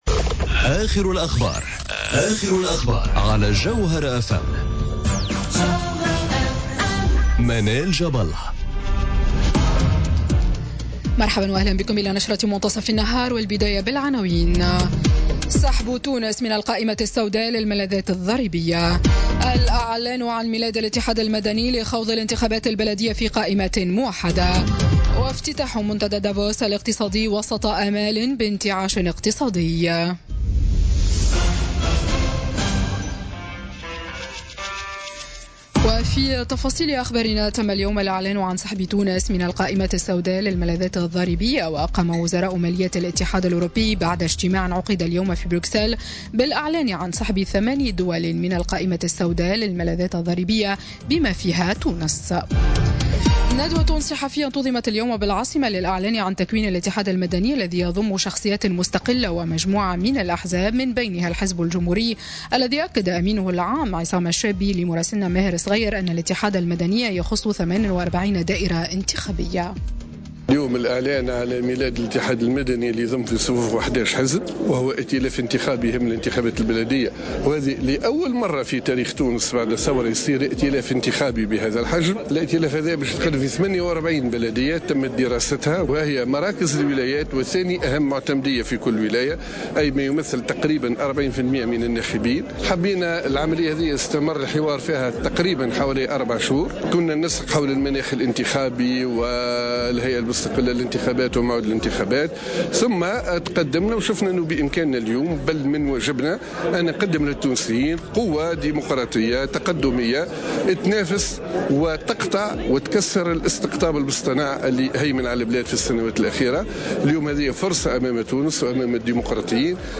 نشرة أخبار منتصف النهار ليوم الثلاثاء 23 جانفي 2018